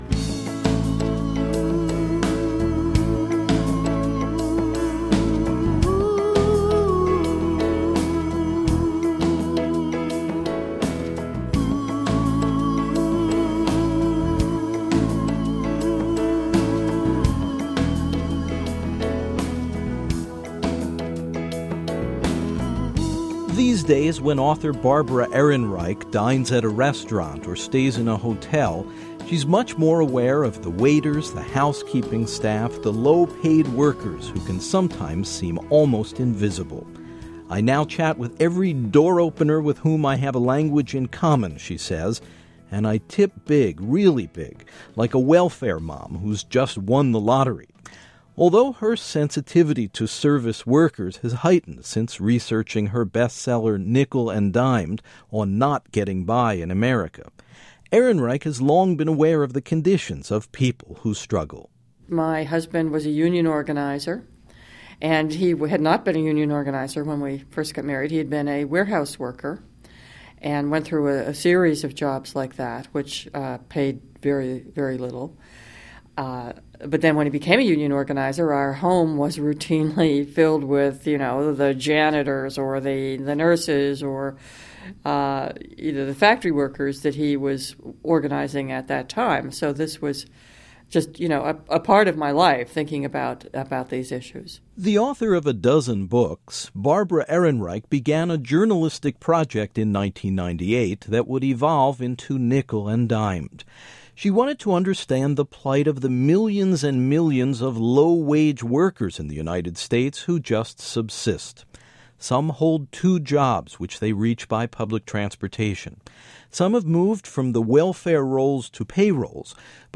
The plight of the working poor who experience harsh treatment on the job and barely subsist is recounted by sociologist Barbara Ehrenreich who took various low-paying jobs to research her best-selling book.